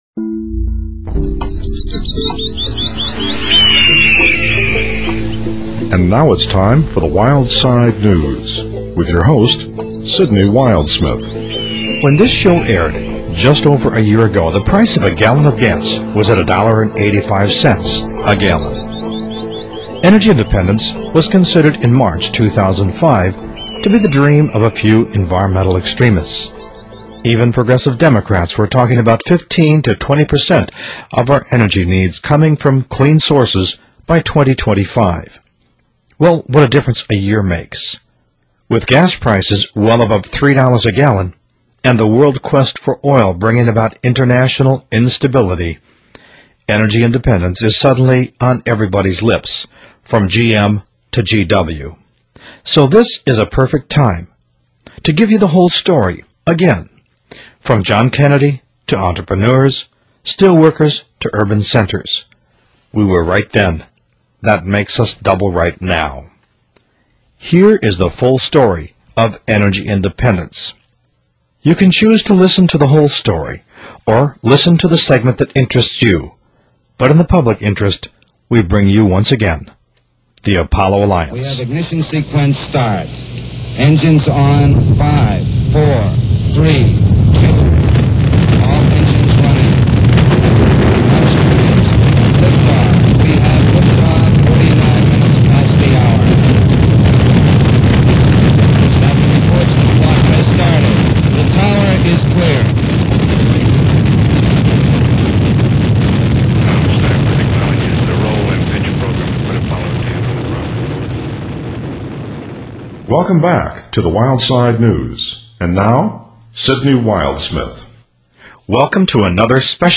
Introduction, including speech by President John F. Kennedy that launched the Apollo Project to put a man on the moon in 10 years.